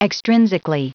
Prononciation du mot extrinsically en anglais (fichier audio)
Prononciation du mot : extrinsically